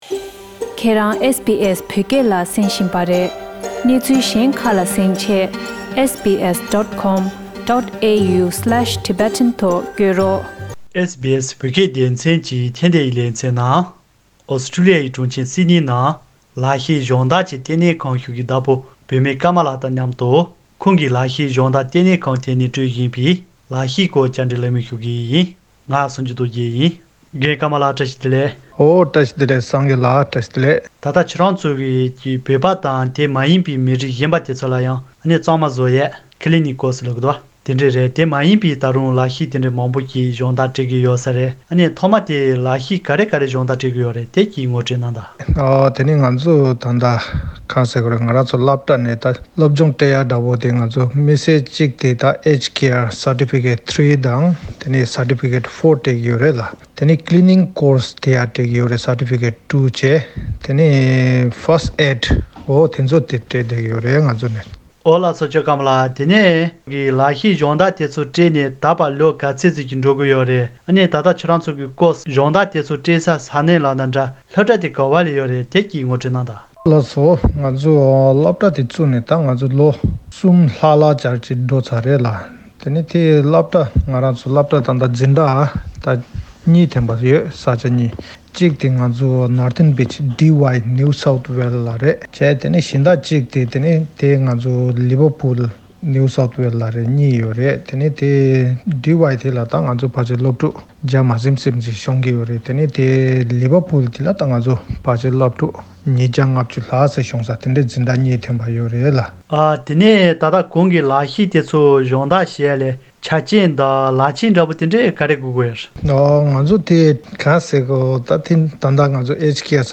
བཅར་འདྲི་གླེང་མོལ་ཞུས་པ།